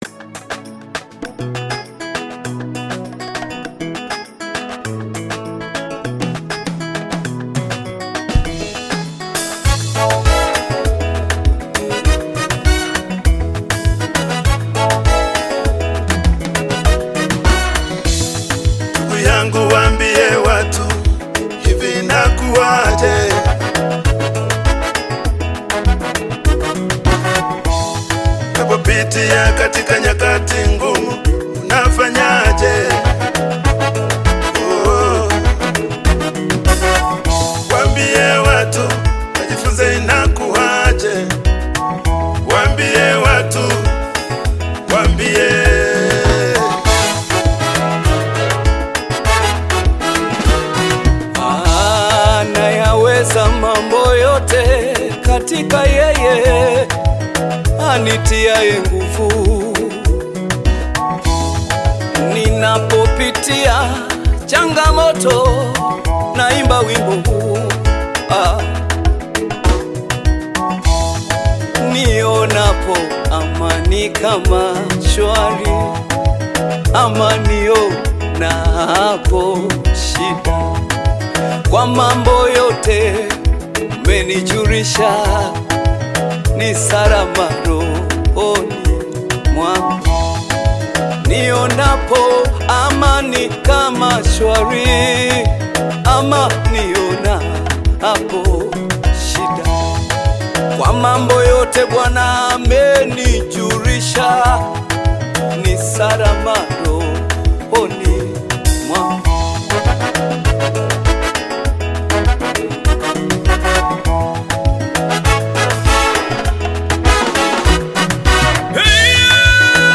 Tanzanian gospel singer and songwriter
then this gospel song must be added to your gospel playlist.